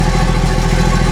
GeneratorOn.ogg